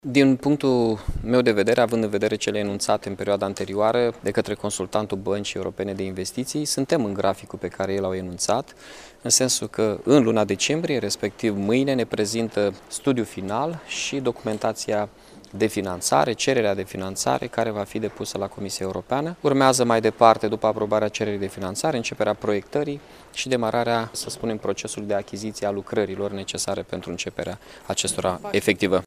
Primarul Mihai Chirica a anunţat, astăzi, într-o conferinţă de presă, că Primăria Municipiului Iaşi va face un credit de 34 de milioane de euro pentru a construi căile de acces către viitorul spital regional de urgenţe.